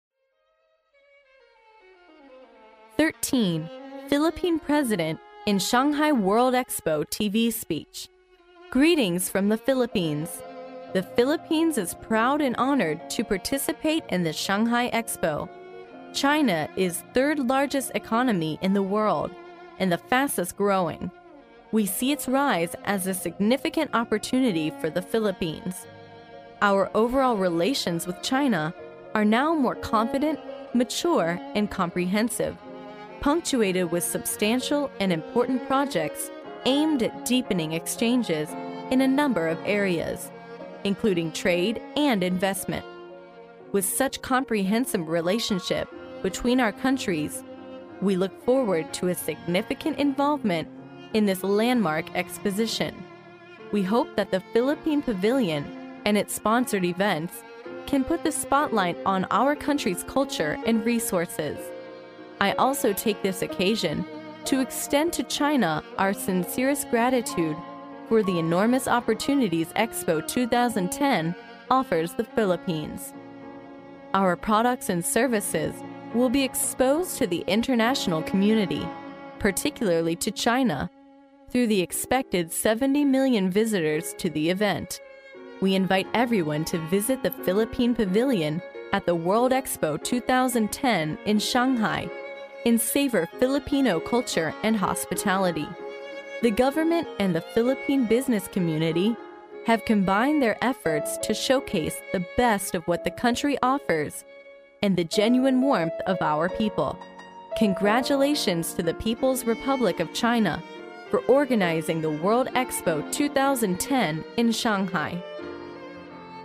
历史英雄名人演讲 第63期:菲律宾总统在上海世博会的电视致辞 听力文件下载—在线英语听力室